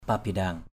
/pa-ba-ɗa:ŋ˨˩/ (cv.) pabindang F%b{Q/
pabindang.mp3